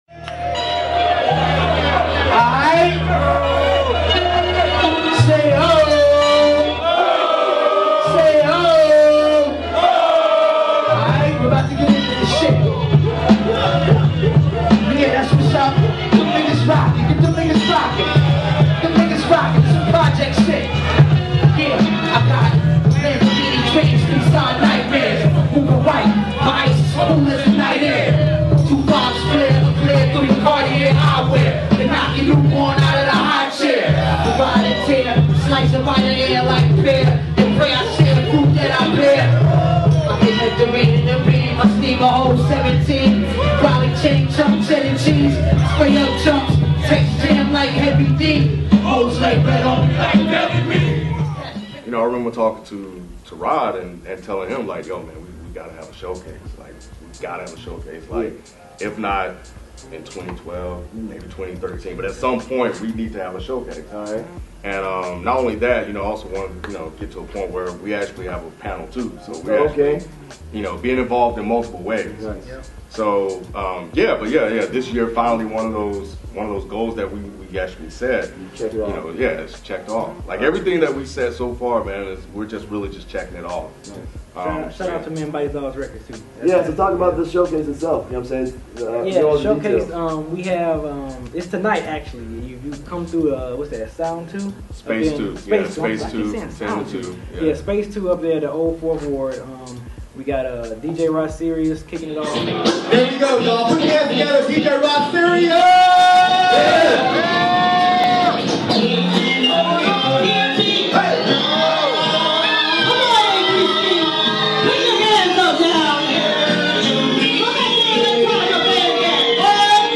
DEHH x Man Bites Dog Records Showcase Pt.1 | A3C Hip Hop Festival 2013